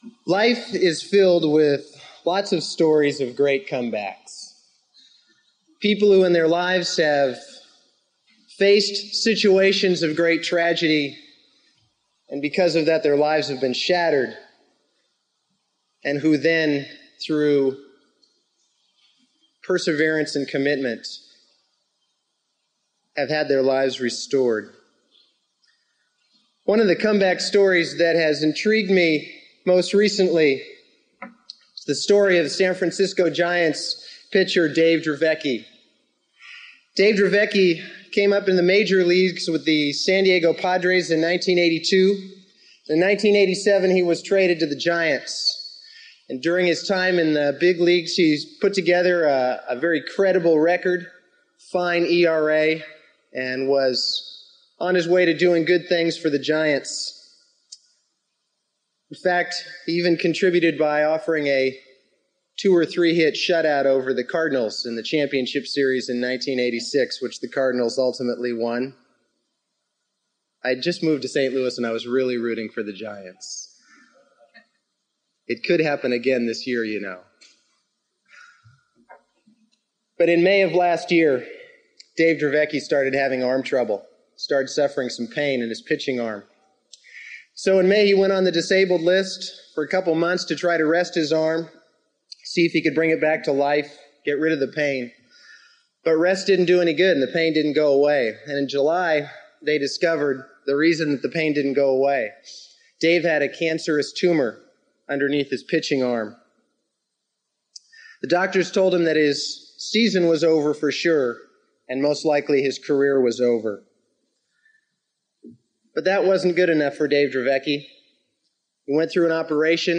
SERMON: Jephthah, the Comeback Player of the Year